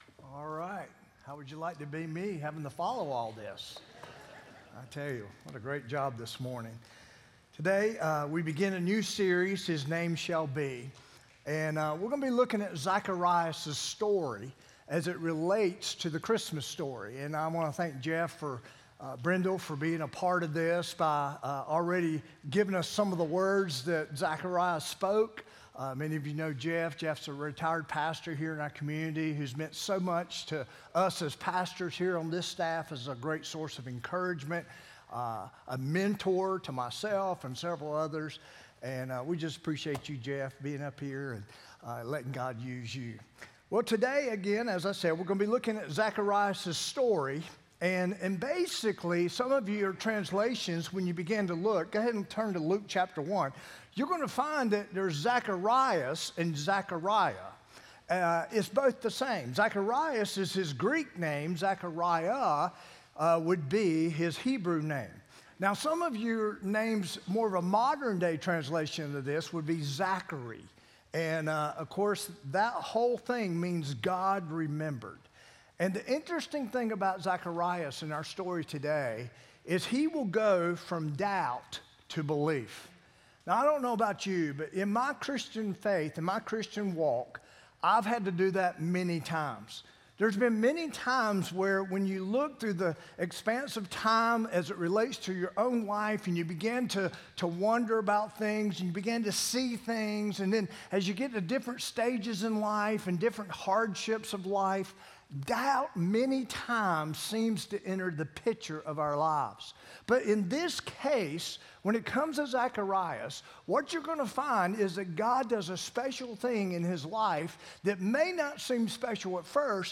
12-1-24-sermon-audio.m4a